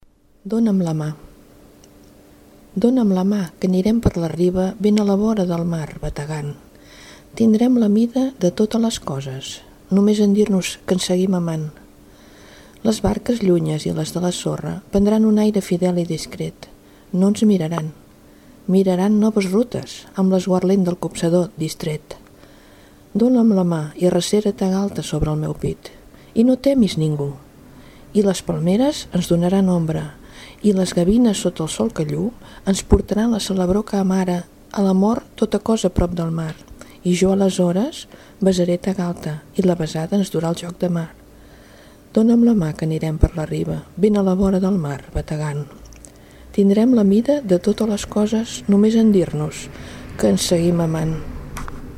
Poema